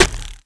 arrow_hit4.wav